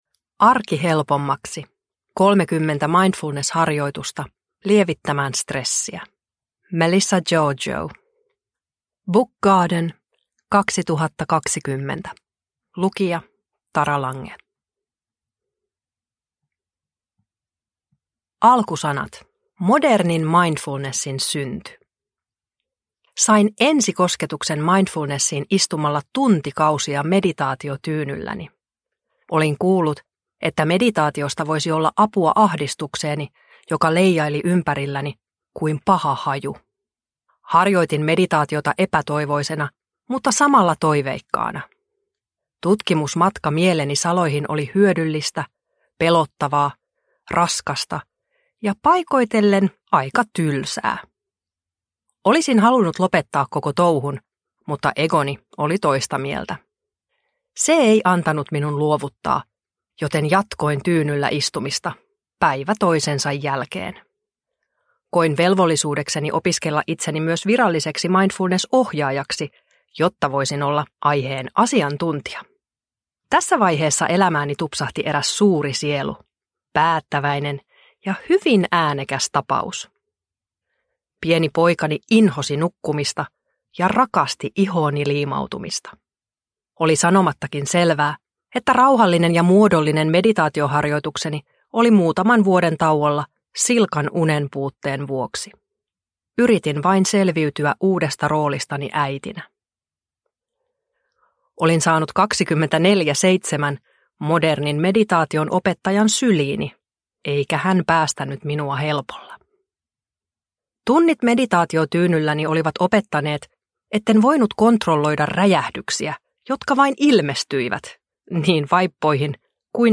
Arki helpommaksi – Ljudbok – Laddas ner